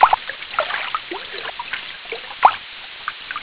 Sons da natureza 18 sons
aguacorrente1.wav